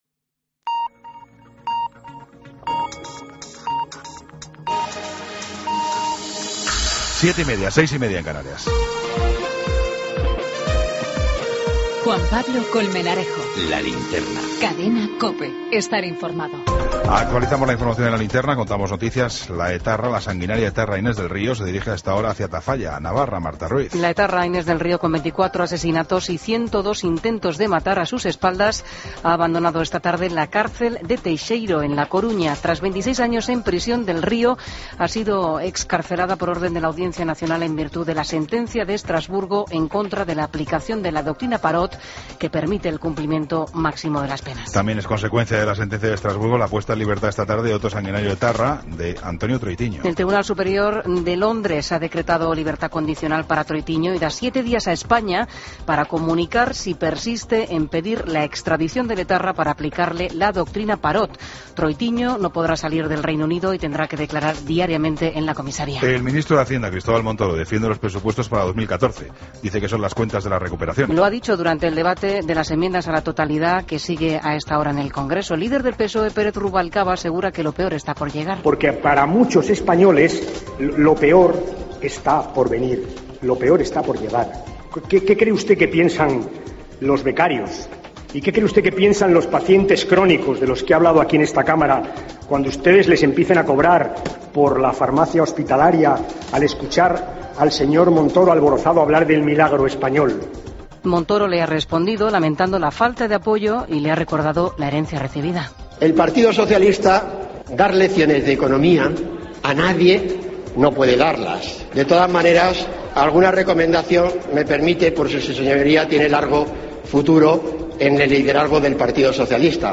AUDIO: Toda la información con Juan Pablo Colmenarejo